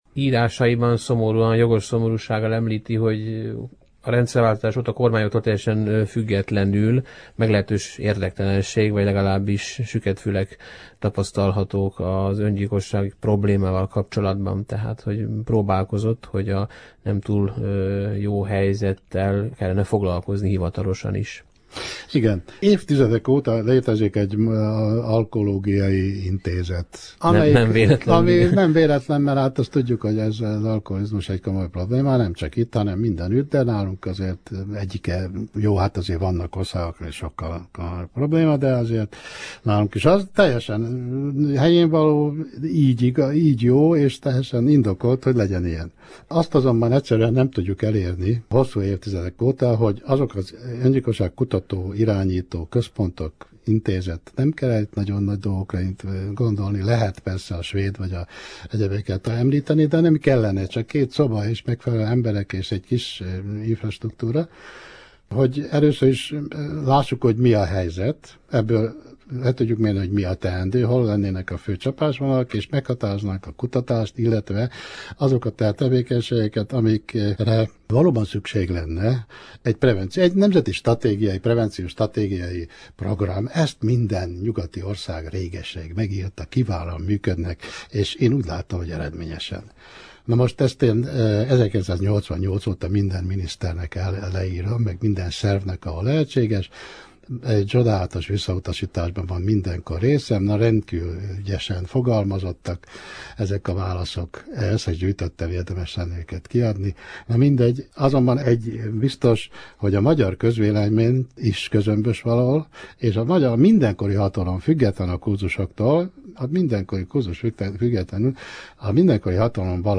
Besz�lget�s